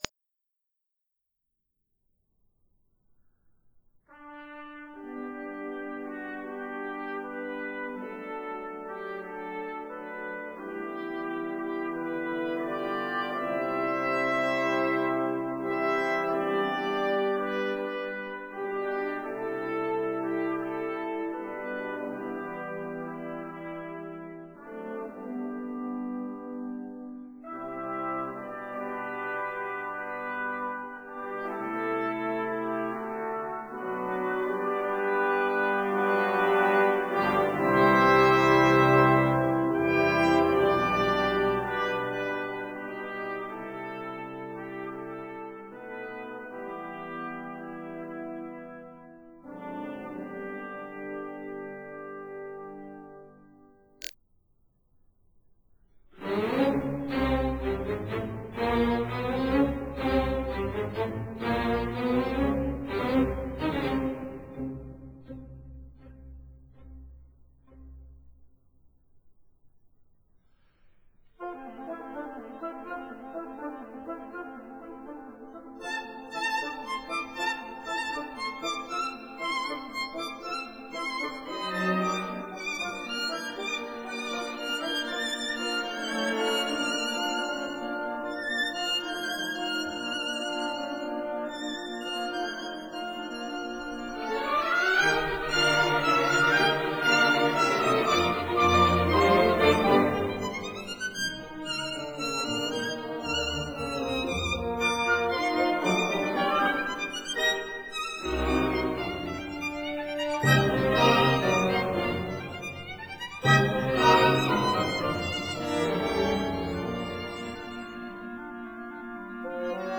Concerto for Violin and Orchestra